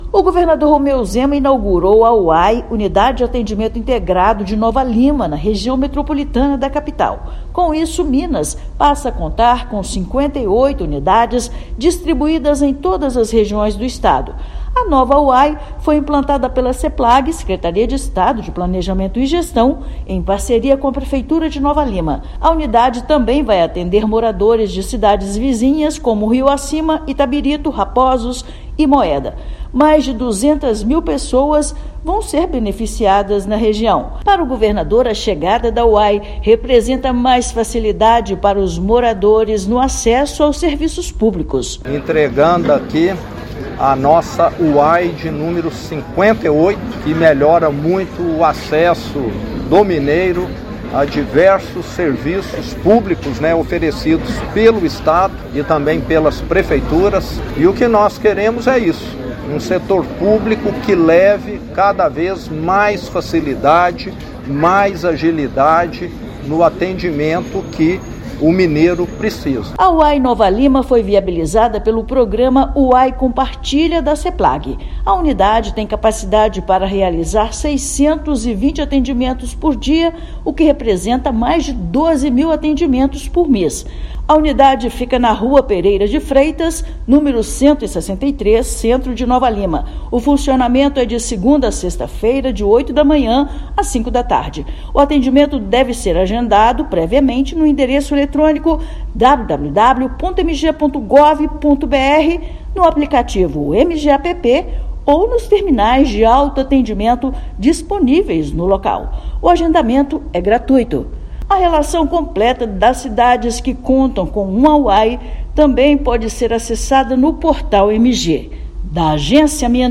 Unidade funcionará no modelo UAI Compartilha, da Seplag, em parceria com a prefeitura; estado passa a contar agora com 58 UAIs. Ouça matéria de rádio.